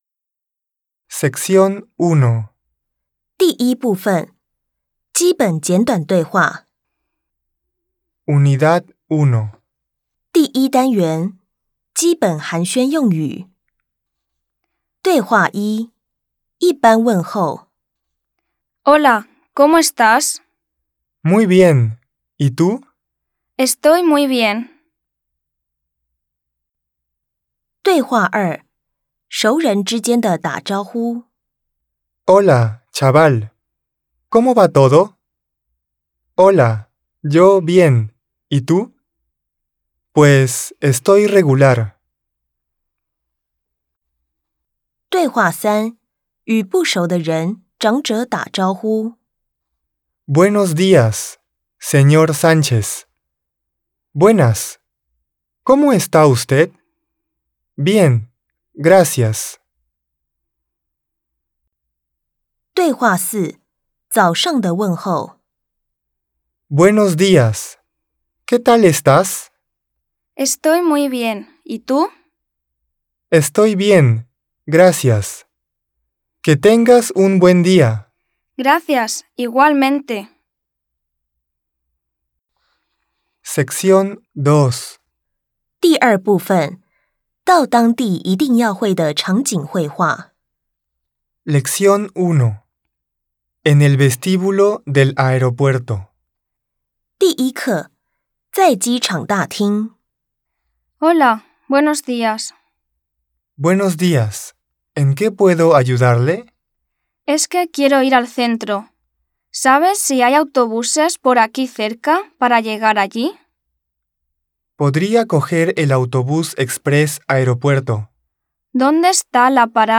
【特點7】附會話及單字朗讀音檔QR碼連結